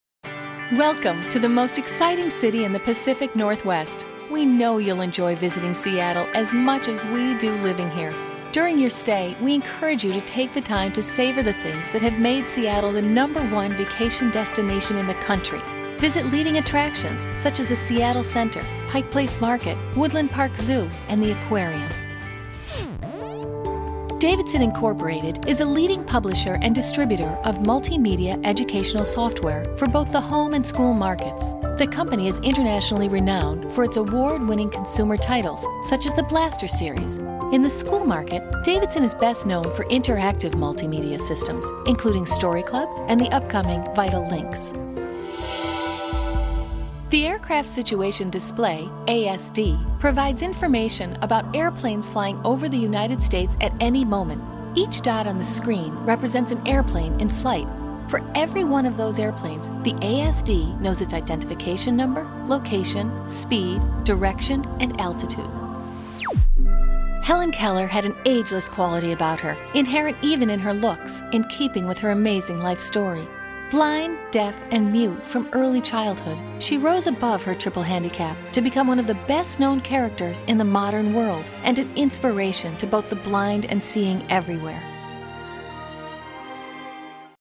Clear, educated, classic, female voice talent with voice range from 20-40\'s.
englisch (us)
Sprechprobe: eLearning (Muttersprache):